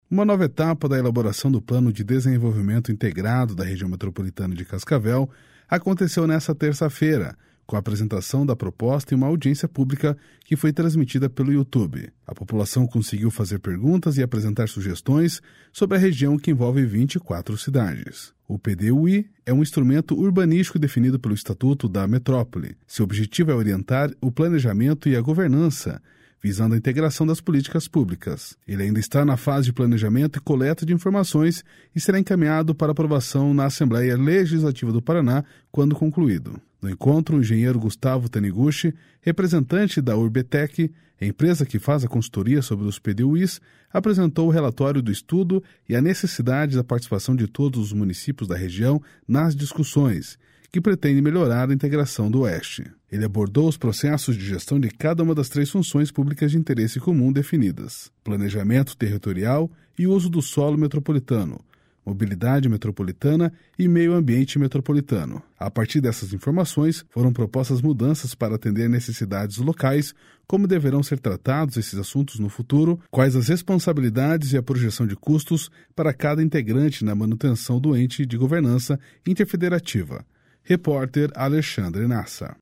(Repórter